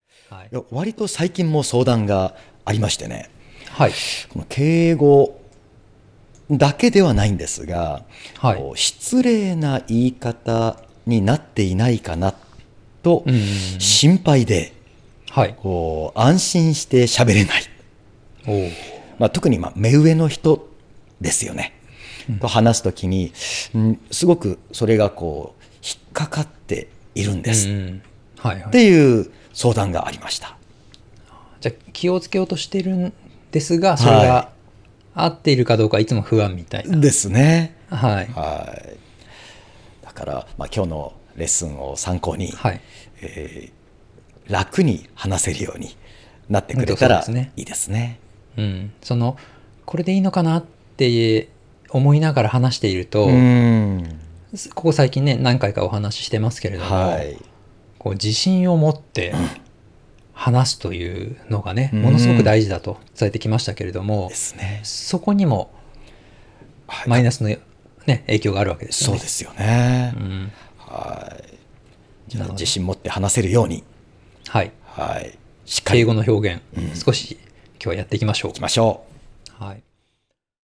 音声講座